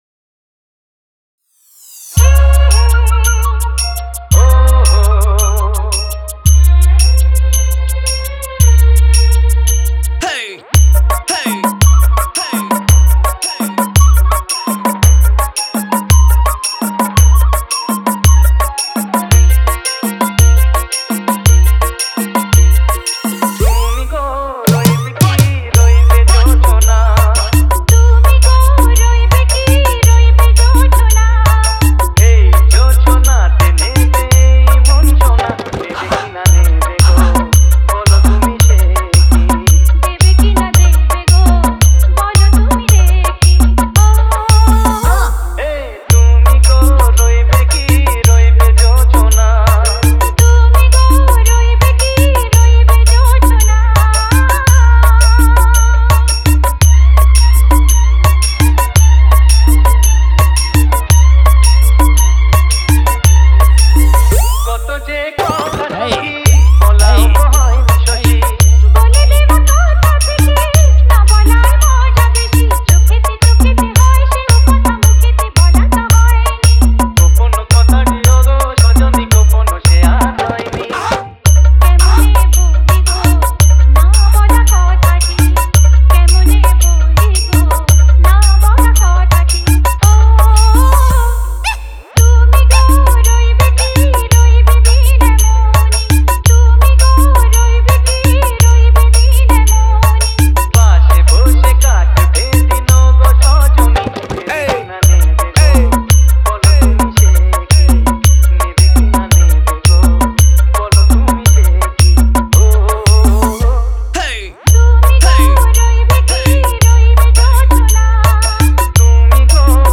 Bengali Humbing Dance Mix